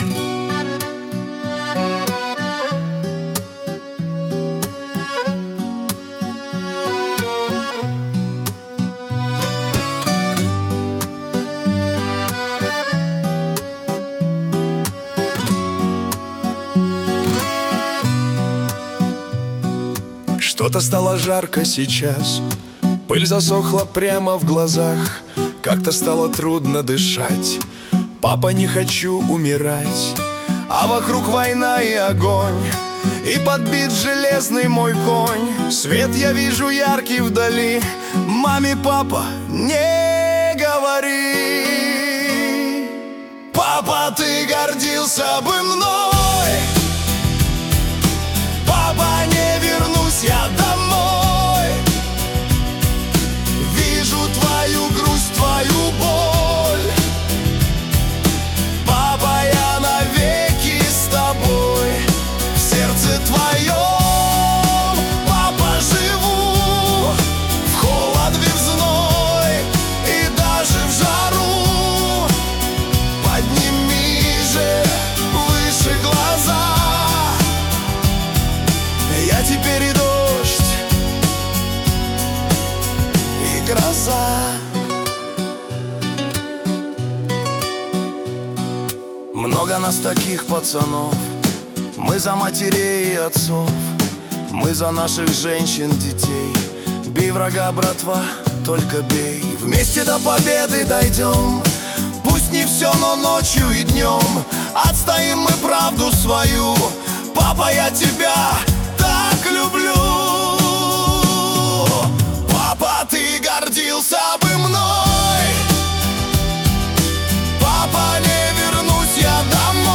Качество: 178 kbps, stereo
Шансон, Русский шансон 2025